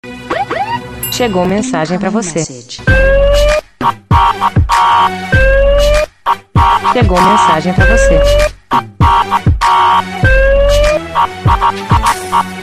Categoria: Toques
Esse também é o som do meme que está bombando nas redes sociais.